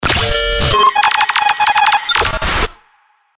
FX-1461-BREAKER
FX-1461-BREAKER.mp3